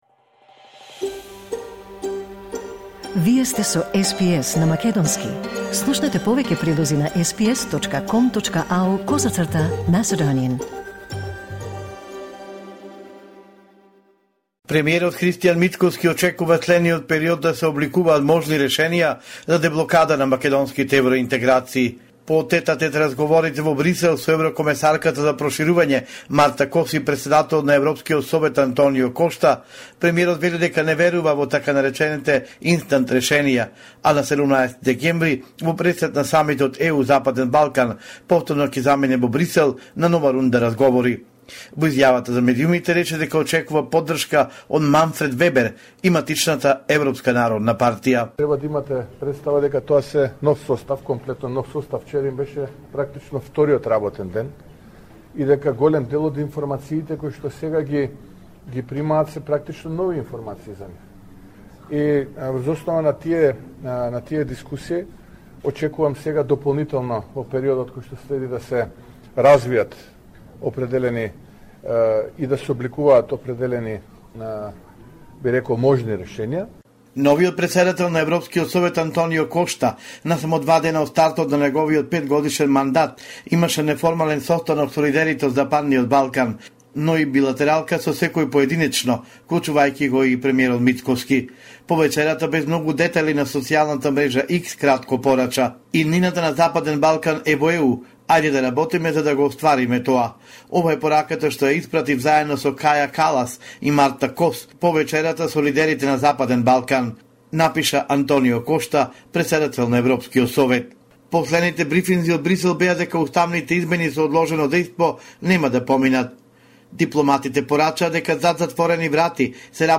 Извештај од Македонија 5 декември 2024